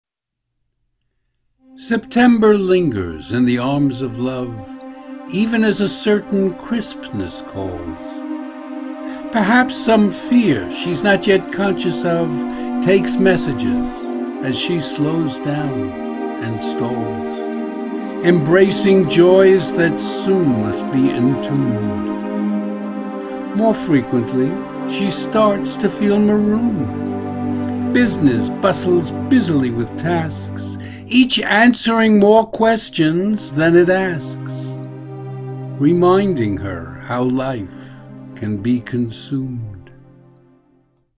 Hear me read the poem as an MP3 file.